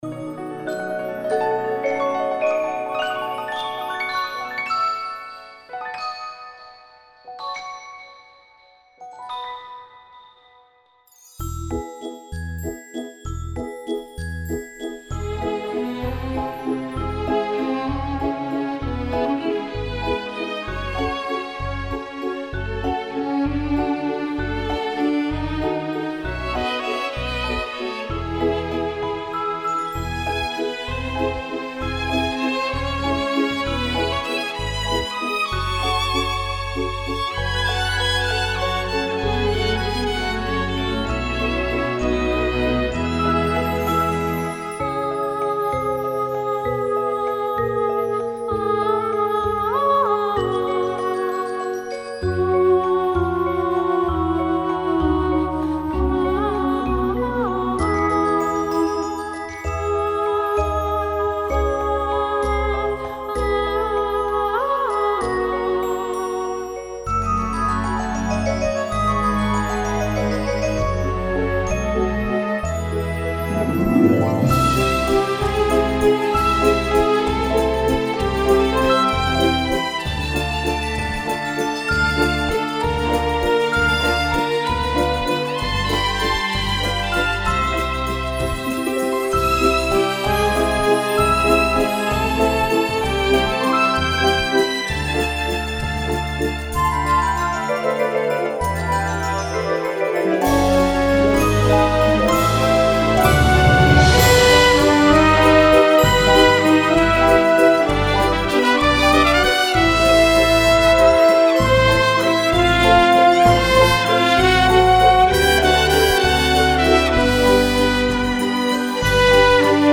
потім на програш (вісім тактів) діти виконують два повороту навколо себе, руки вгорі
потім на програш стають в одну лінію-шеренгу і виконують перед собою короткі швидкі почергові махи руками, на кульмінацію можна опуститися на одне коліно, продовжуючи рухи руками, на заключний акорд, руки підняти вгору і розведіть у сторони.